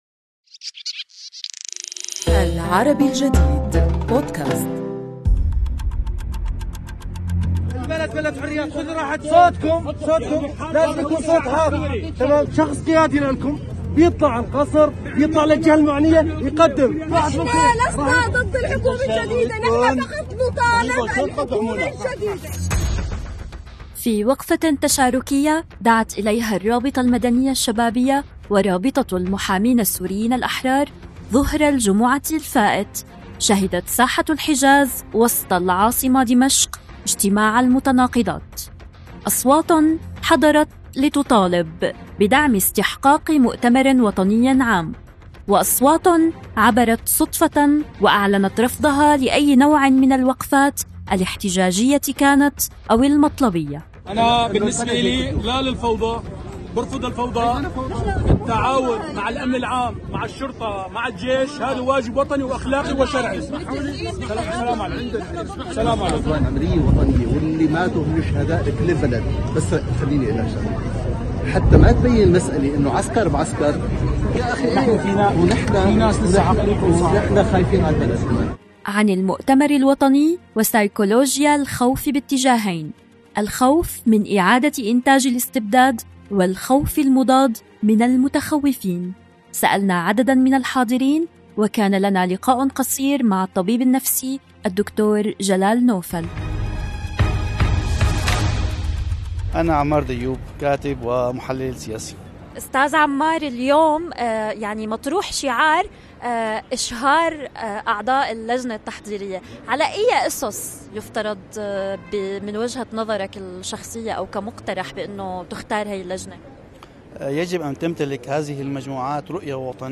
في وقفة تشاركية دعت إليها الرابطة المدنية الشبابية ورابطة المحامين السوريين الأحرار، ظهر الجمعة الفائت، شهدت ساحة الحجاز وسط العاصمة دمشق اجتماع المتناقضات. أصوات حضرت لتطالب بدعم استحقاق مؤتمر وطني عام، وأصوات عبرت صدفة، وأعلنت رفضها أي نوع من الوقفات الاحتجاجيةِ كانت أو المطلبية.